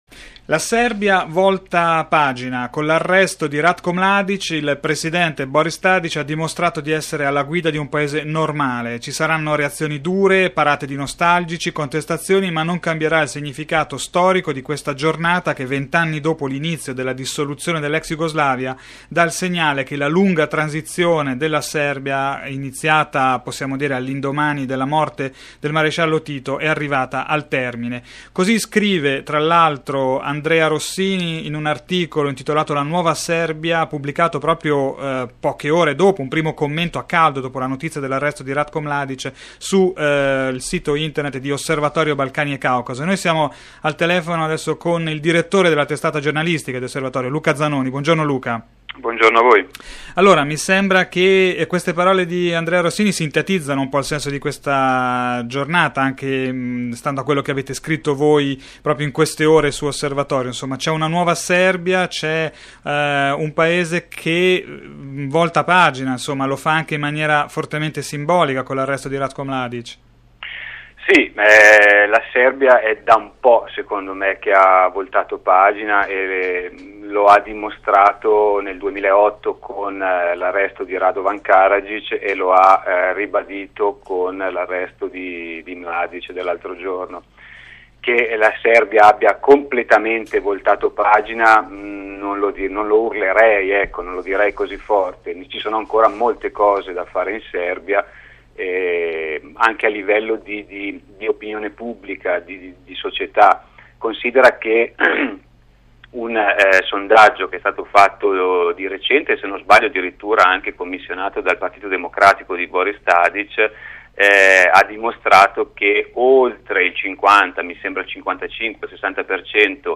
l’intervista